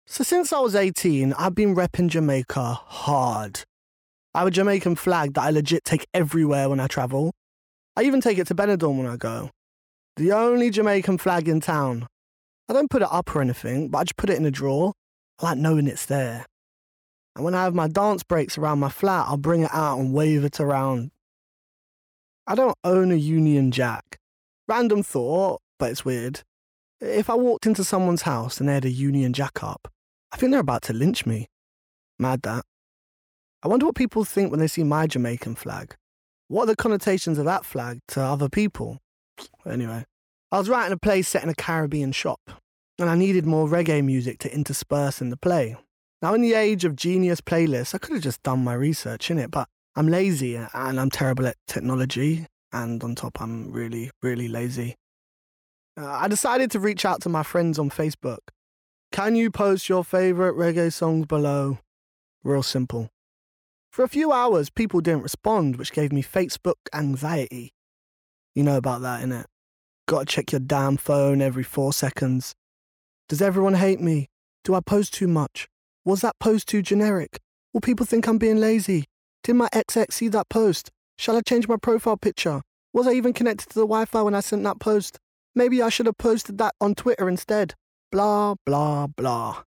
• Male
Accent
• London